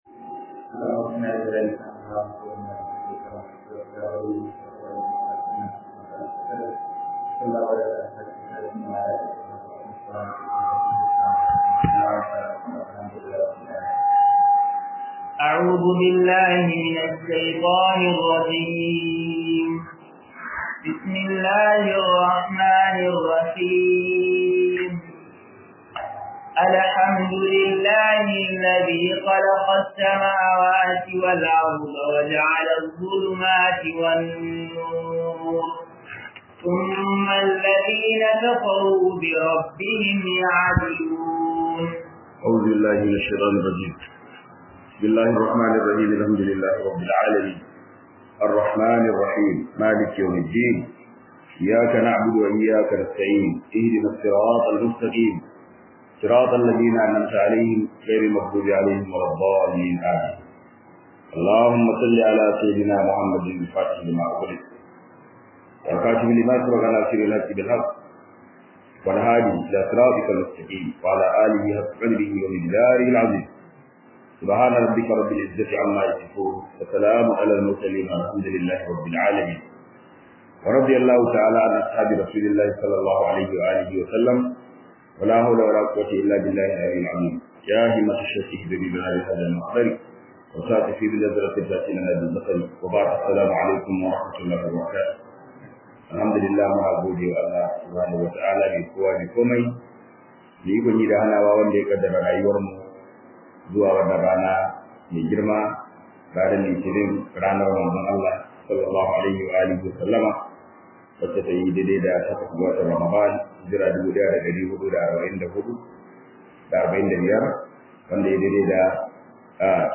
Tafsir